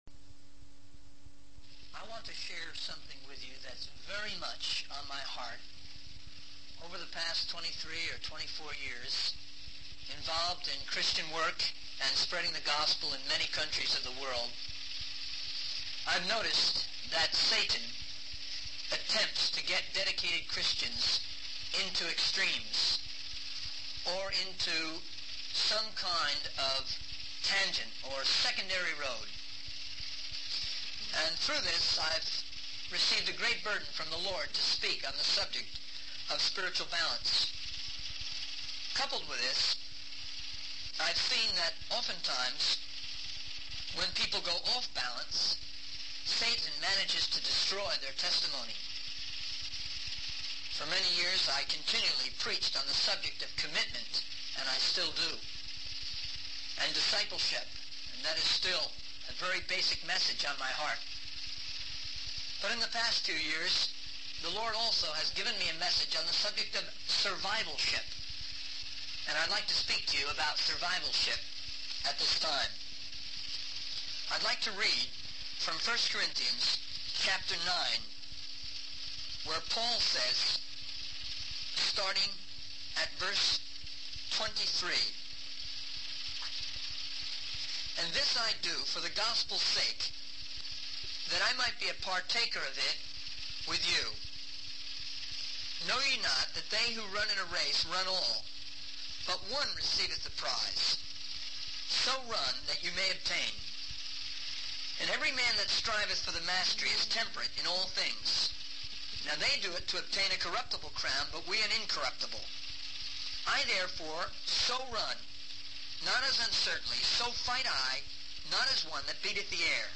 In this sermon, the speaker emphasizes the importance of learning to be heard in our Christian life. He shares a story of a young man who made a mistake that resulted in a plane crash and the emotional struggle he went through.